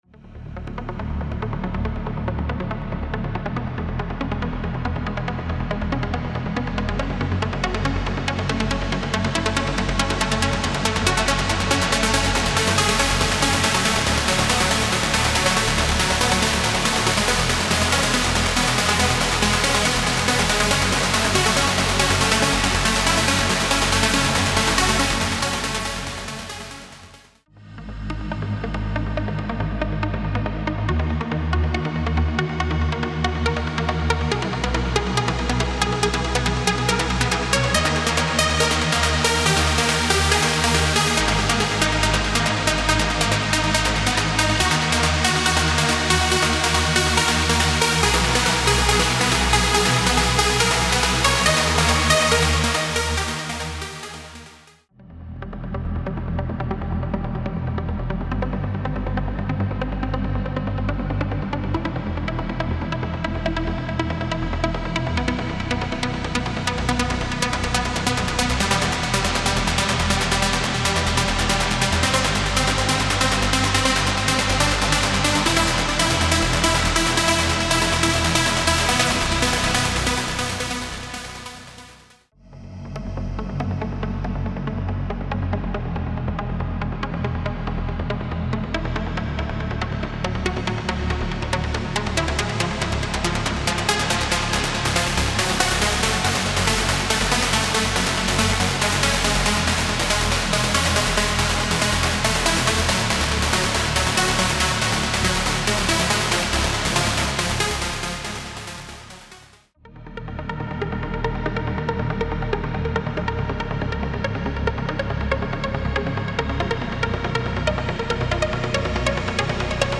Type: Midi Samples
Lead & Bass for any Kit Plus Demo